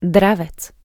dravec.wav